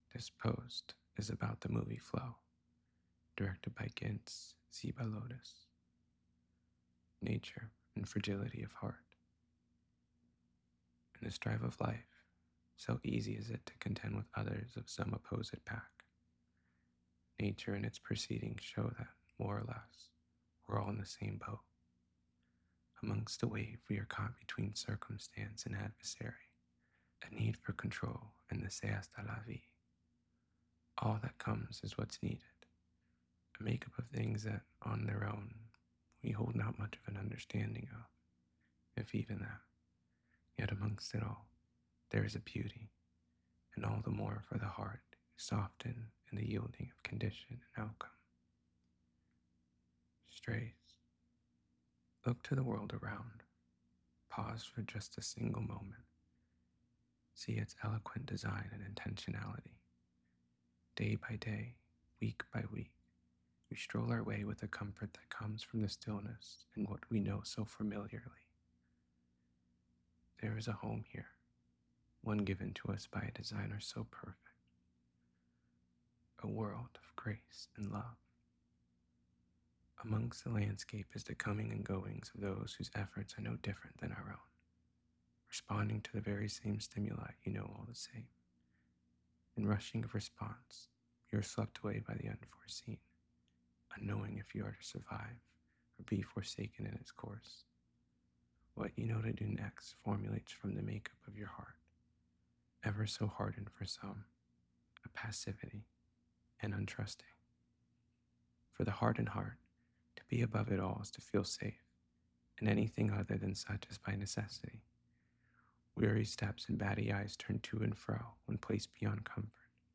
flow-to-know-a-story-reading.mp3